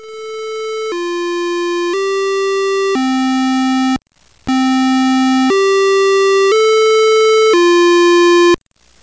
The Test siren can be heard on the
Westminster.mp3